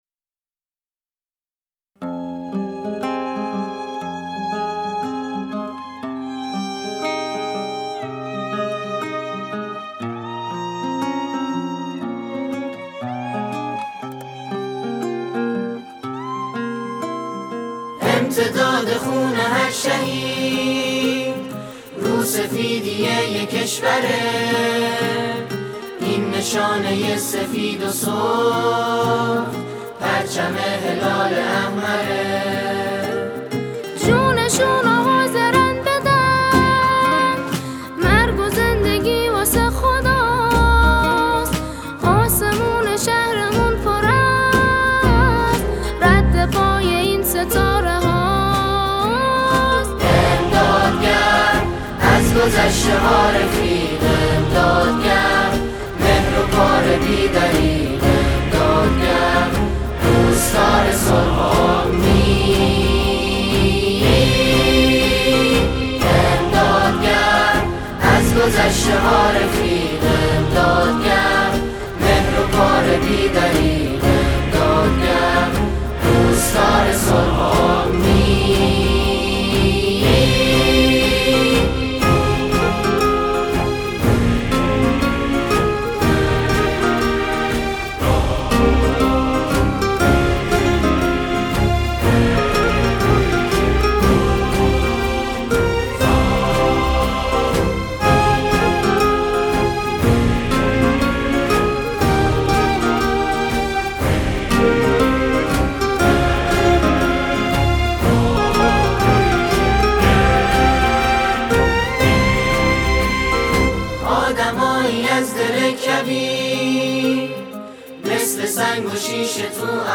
گروه سرود یزد